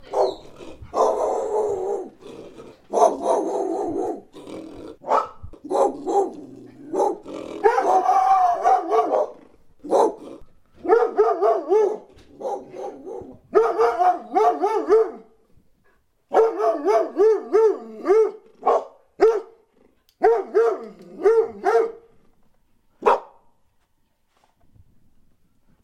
The sound of barking dogs - Eğitim Materyalleri - Slaytyerim Slaytlar
the-sound-of-barking-dogs